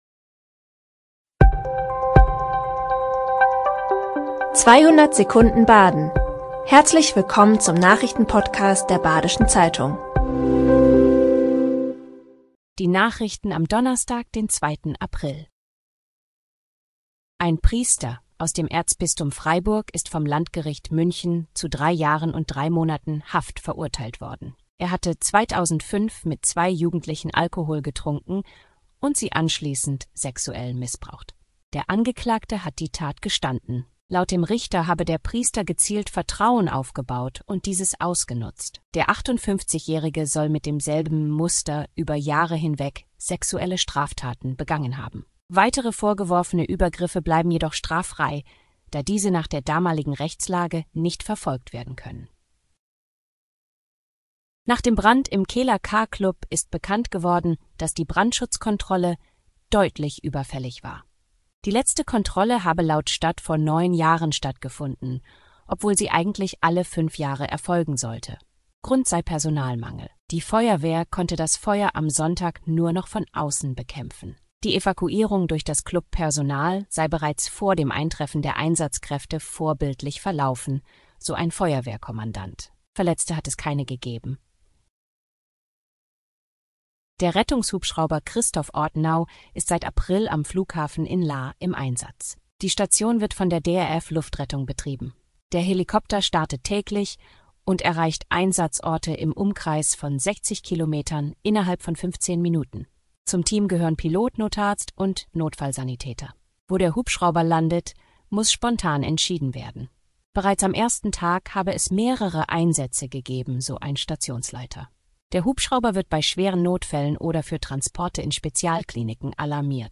5 Nachrichten in 200 Sekunden.
Genres: Daily News, News, Politics